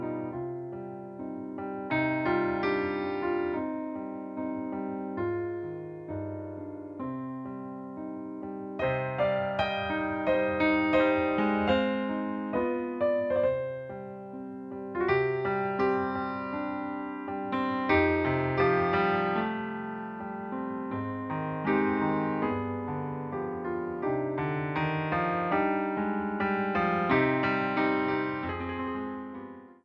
Popular Jewish Music
This piano selection